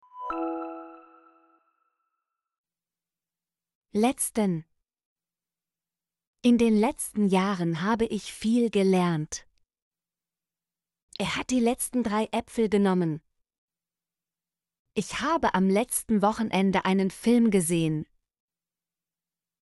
letzten - Example Sentences & Pronunciation, German Frequency List